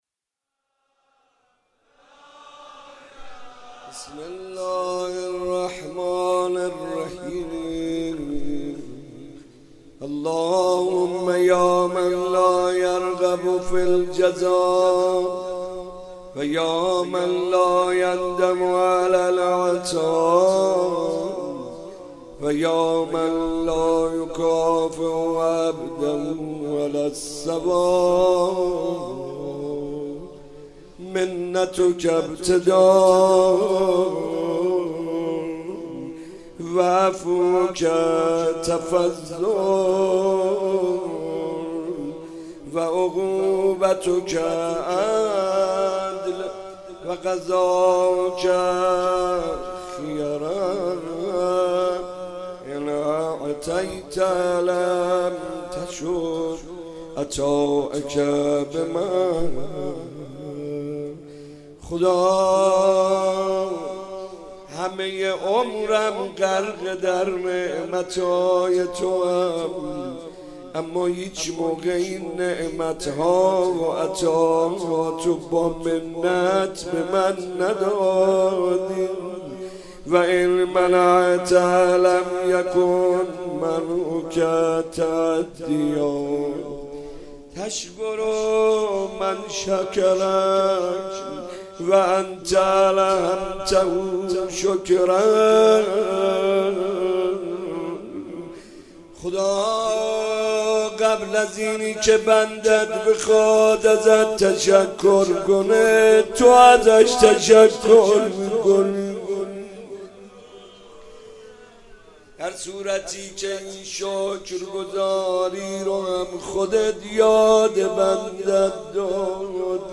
مناجات
روضه